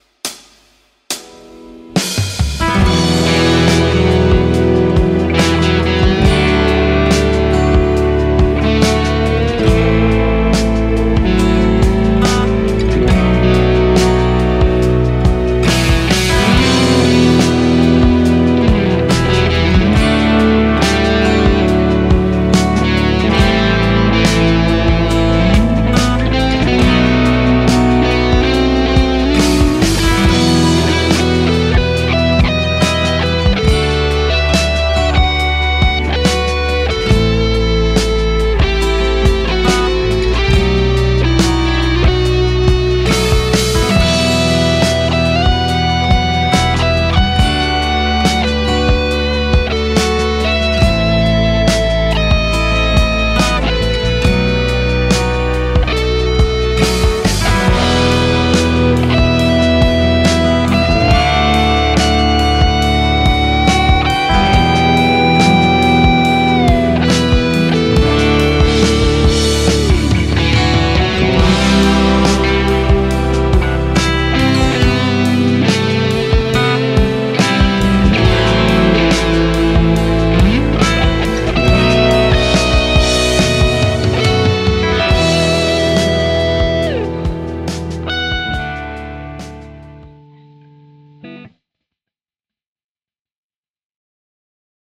Taustana tutunomaista progesoinnutusta:
- Laadi annetun taustan päälle solistinen osuus valitsemallasi instrumentilla